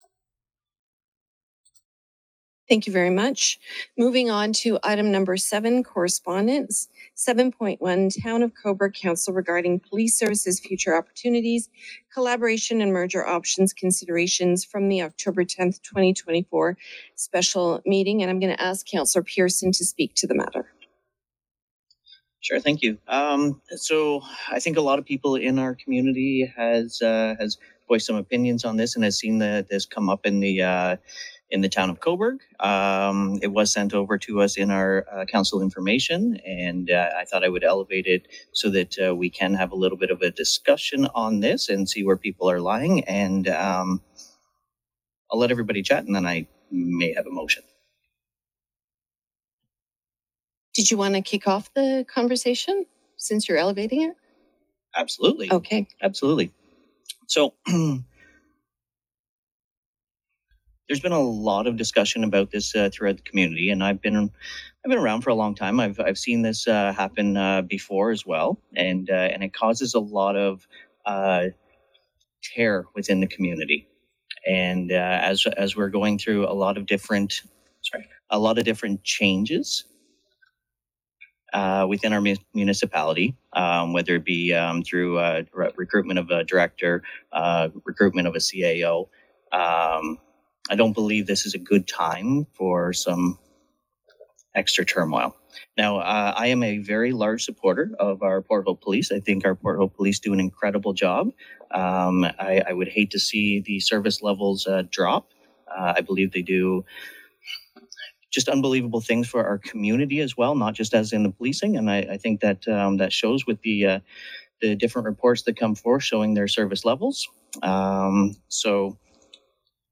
The debate from Port Hope council over a letter from Cobourg regarding policing:
Councillor Adam Pearson emphasized the community’s support for the local police and concerns about adding turmoil during significant municipal changes.